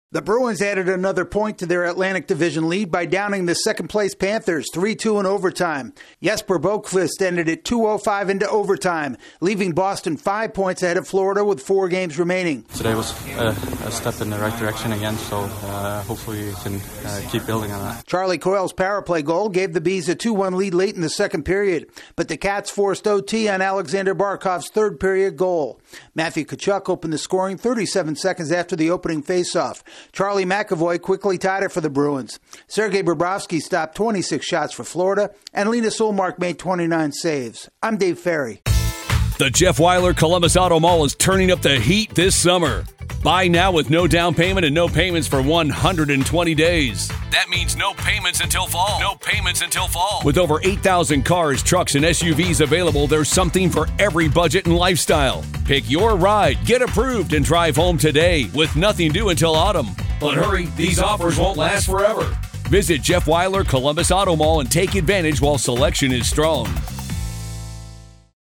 Latest Stories from The Associated Press